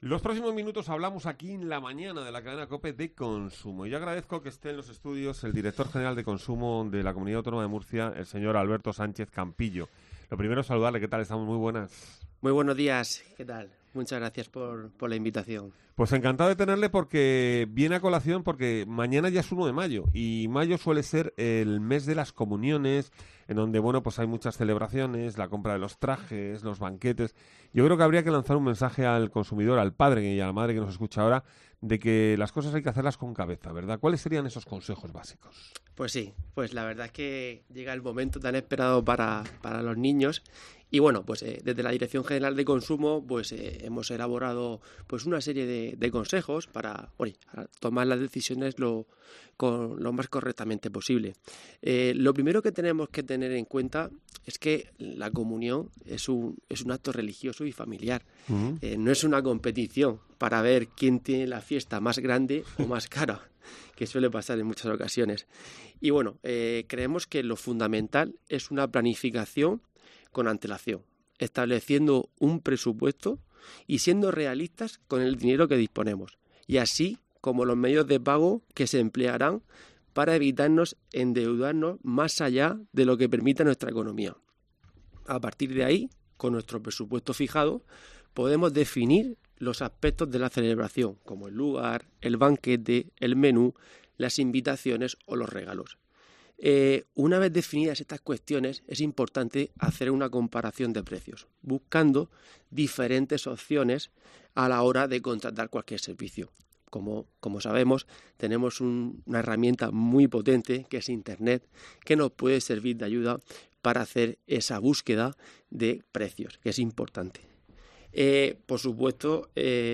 El director general de Consumo nos habla de los gastos previos a una comunión